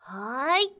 人声采集素材